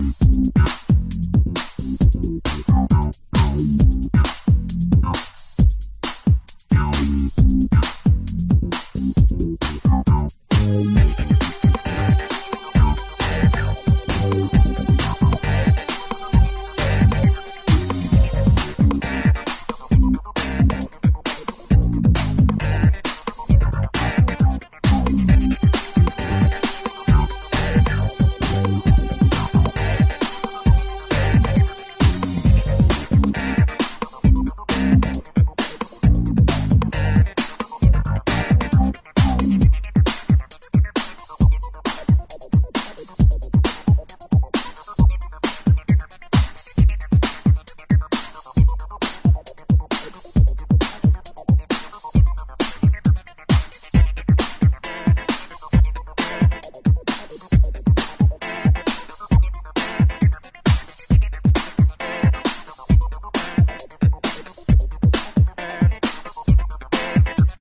Electro Detroit